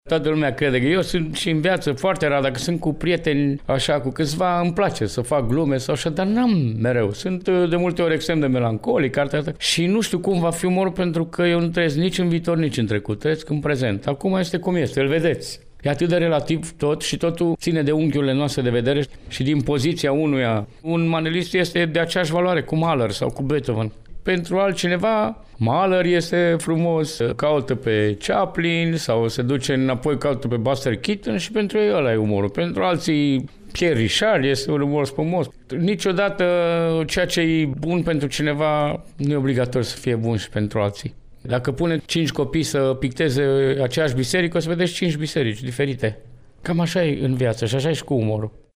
inclusiv un interviu din care vă prezentăm un fragment: